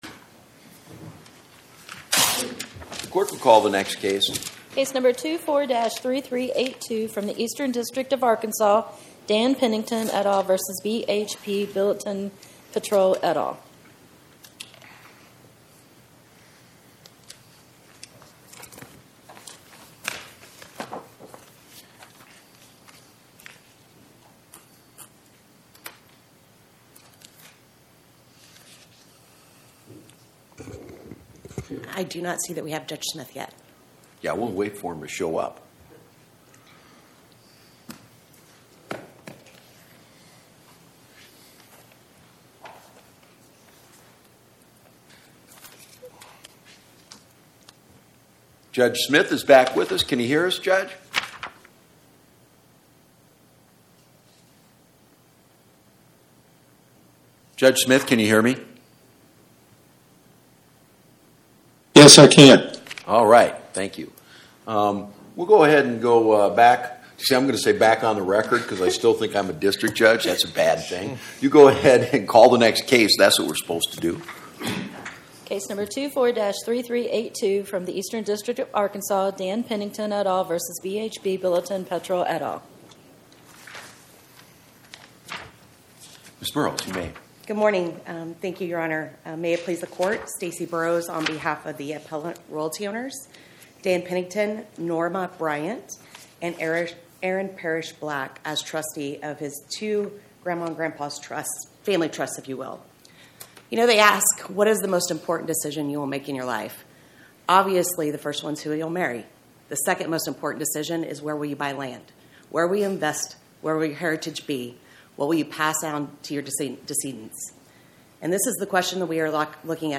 Oral argument argued before the Eighth Circuit U.S. Court of Appeals on or about 01/13/2026